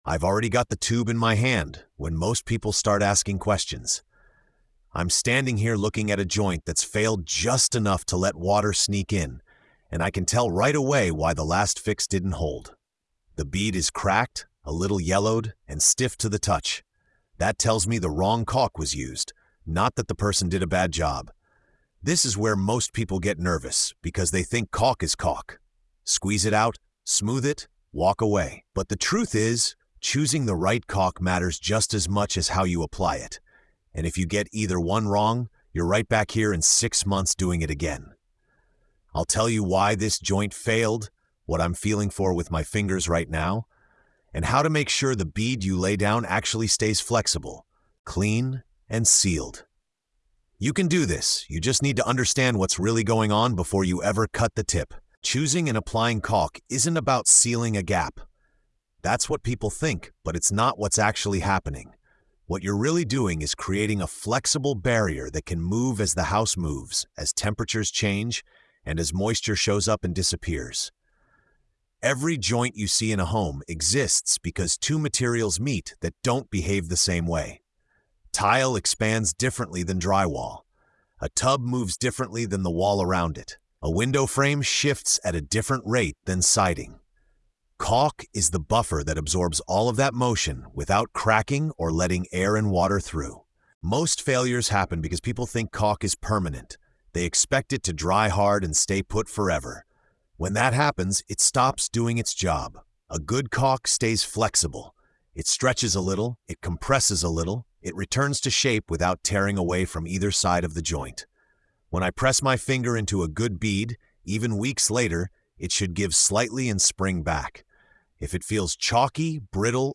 In this episode of TORQUE & TAPE, a seasoned tradesman walks the listener through one of the most misunderstood skills in home maintenance: choosing and applying the right caulk.
Through lived experience and quiet confidence, the episode breaks down why caulk fails, how the wrong choice leads to repeat repairs, and what professionals see that most homeowners miss. The tone is calm, grounded, and reassuring, focused on empowering the listener to stop guessing and start working with intention.